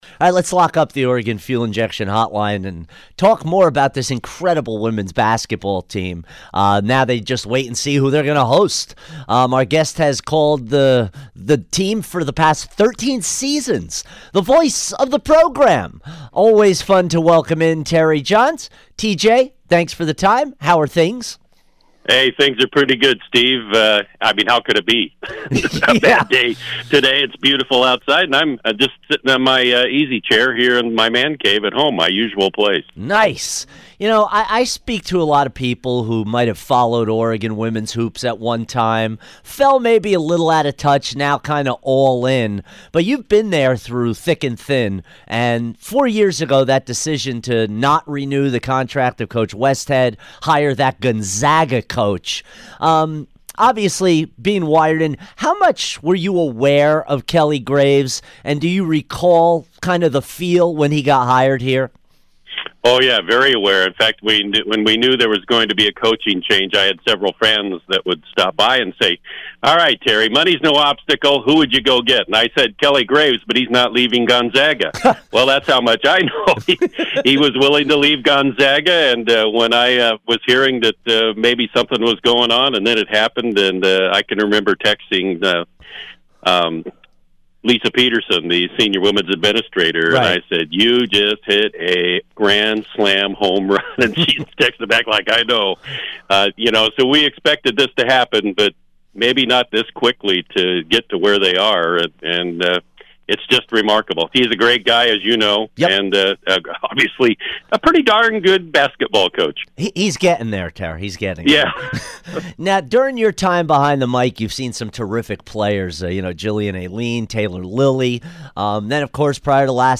Interview 3-6-18